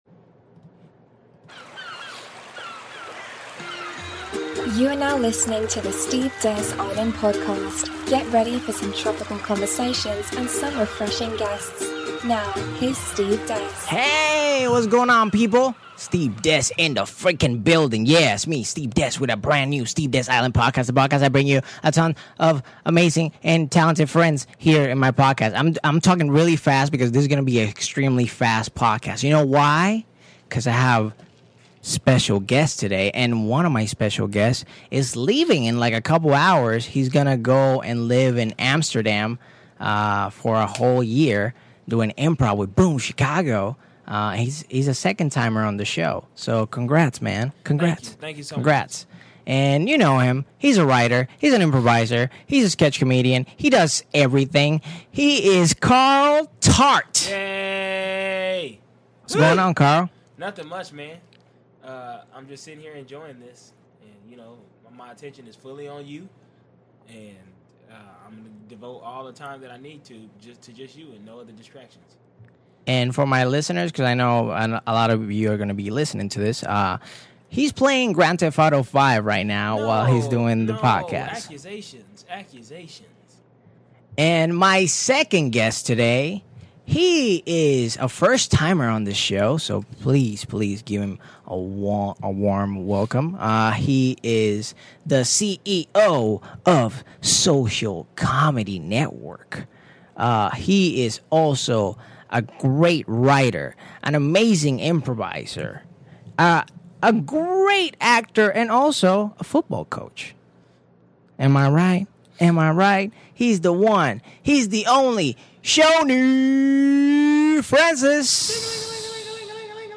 In this episode, we have two hilarious actors, writers, comedians, improvisers and Second City Grads